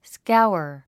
発音
skáuər　スカァワァ